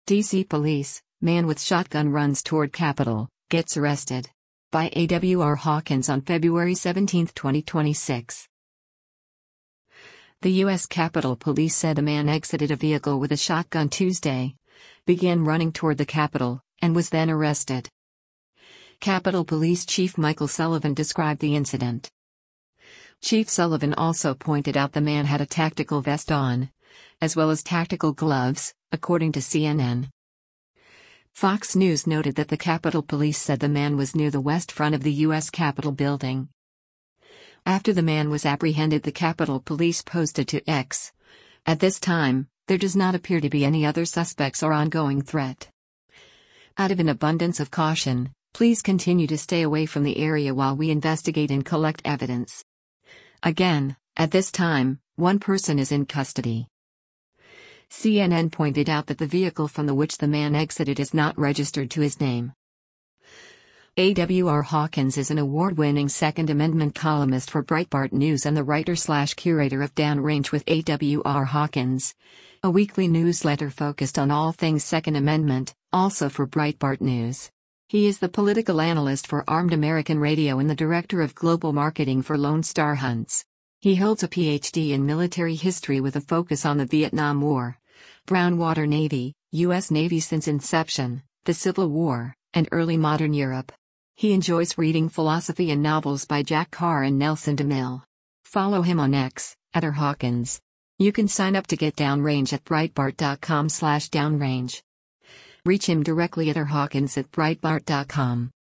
Capitol Police Chief Michael Sullivan described the incident: